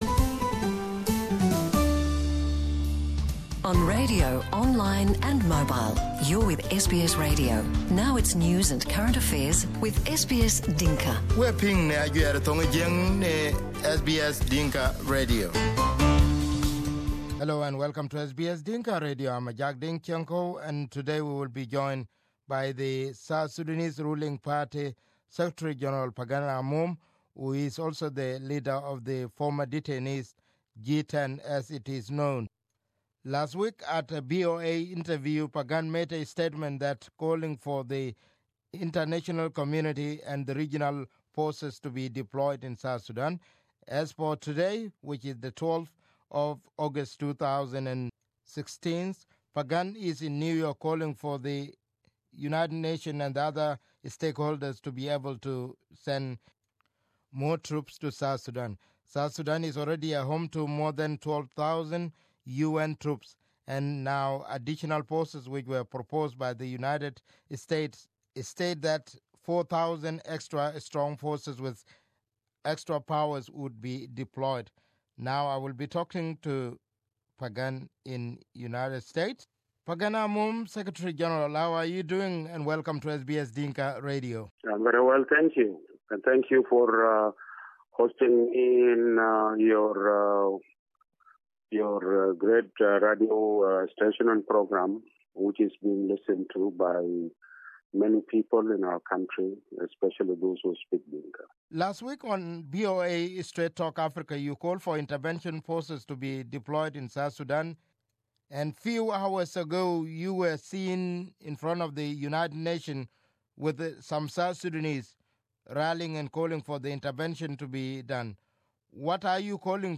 In an exclusive interview on SBS Dinka Radio, Pagan blamed both warring parties for dragging the country into a conflict. Here is the interview.